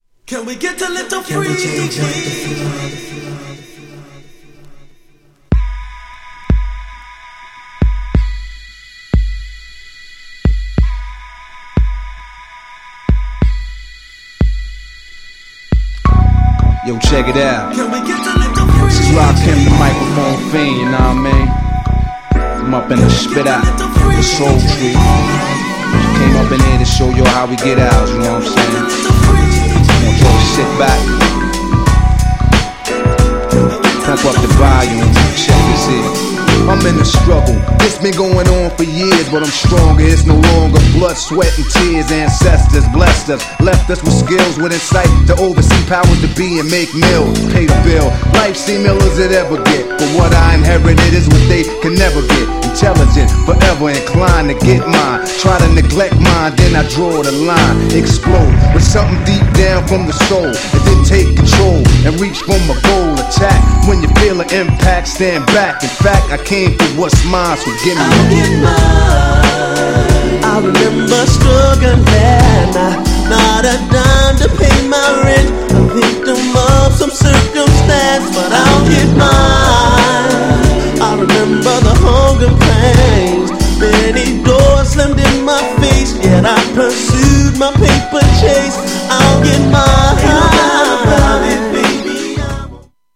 極上ミディアム!!
GENRE R&B
BPM 71〜75BPM